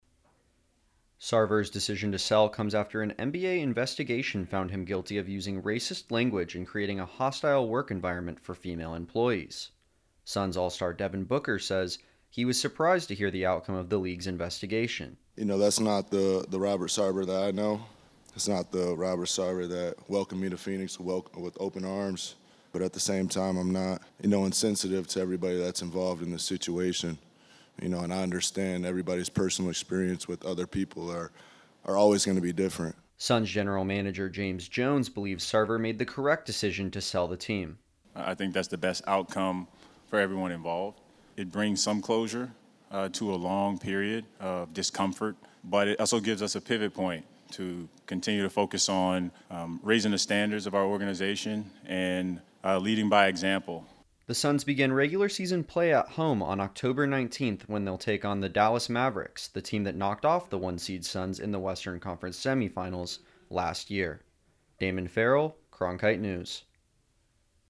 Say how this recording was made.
Suns-Media-Day-Wrap.mp3